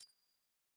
sfx-exalted-hub-banner-button-click.ogg